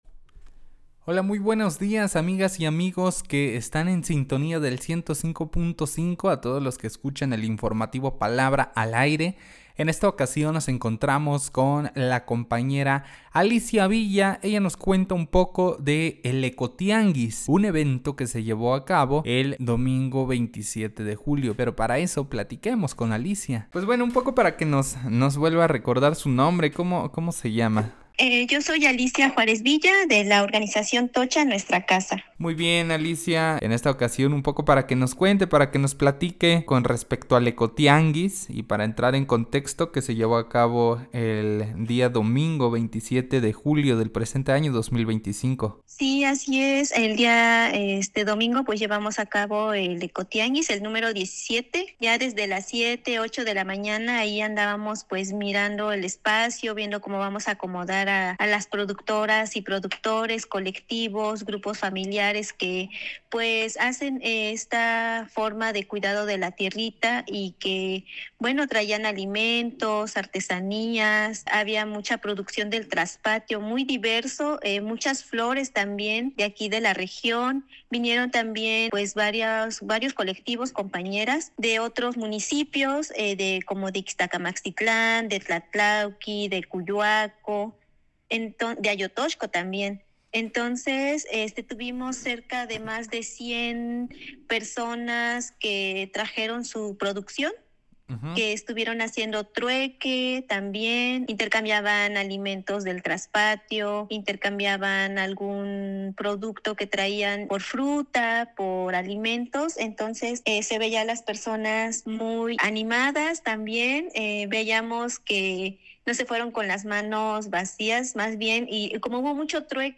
El reporte